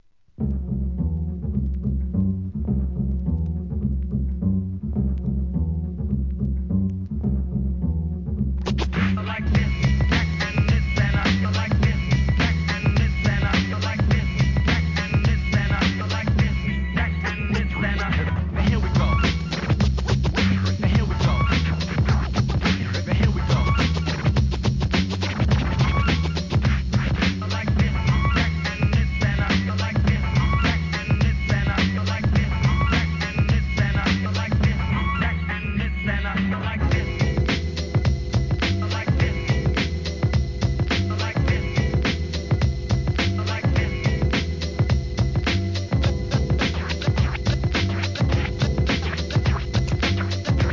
HIP HOP/R&B
良質ブレイクビーツ集!!